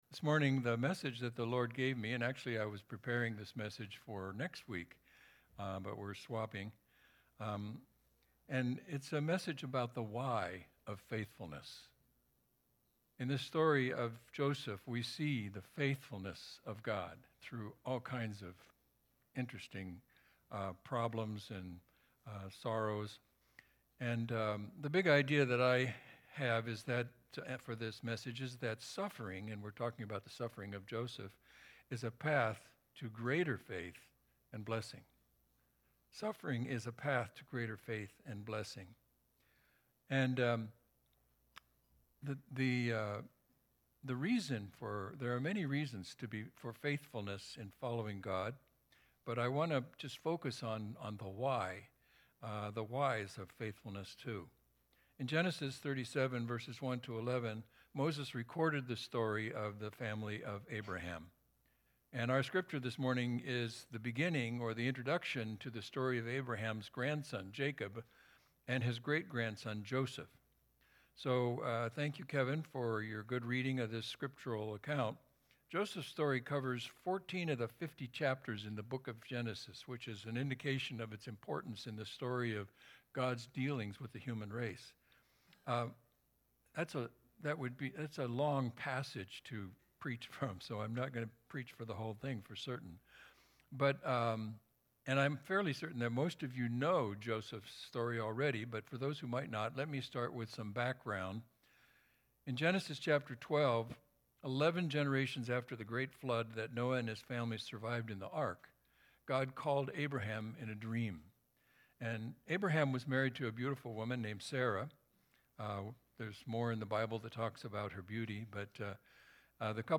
Special Sermon Big Idea